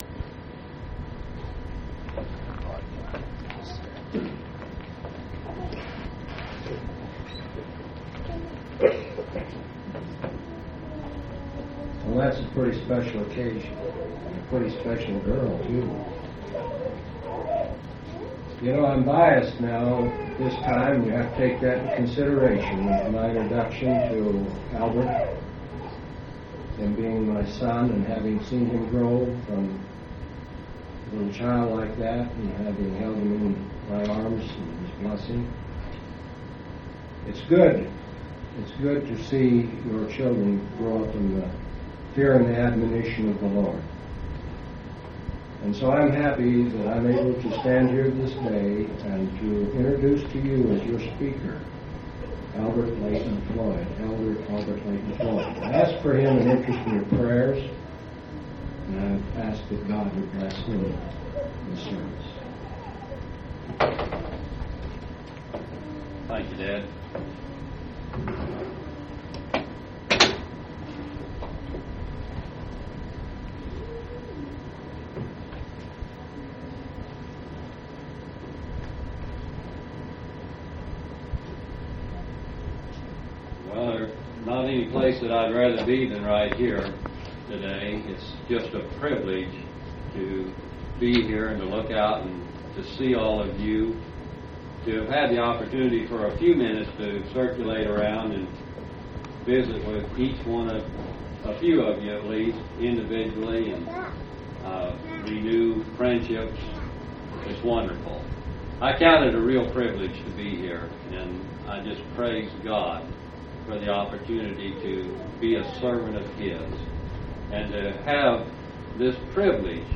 8/11/1984 Location: Missouri Reunion Event